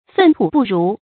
粪土不如 fèn tú bù rú
粪土不如发音